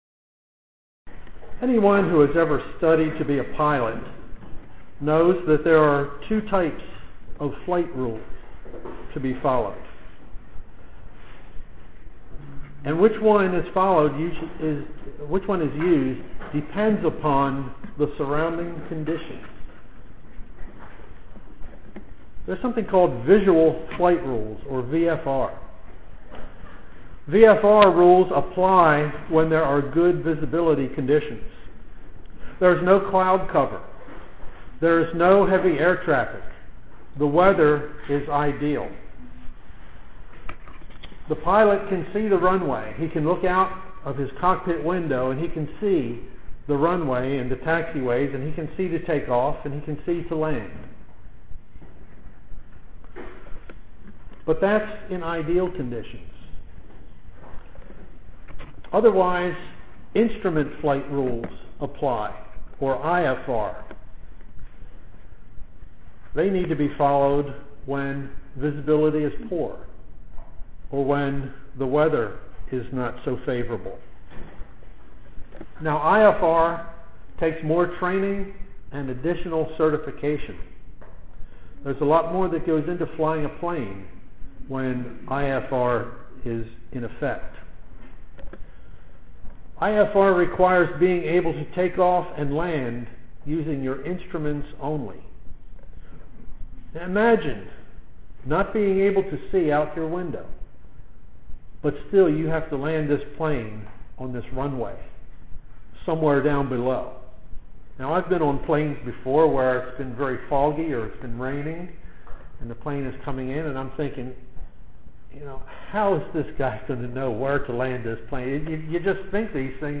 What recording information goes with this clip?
Given in Columbia, MD